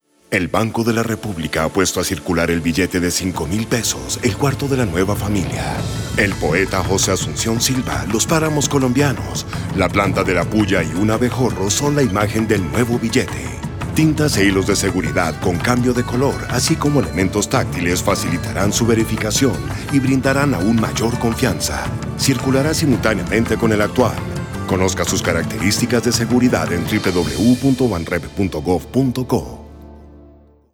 Cuña radial
cuna_radio-5.wav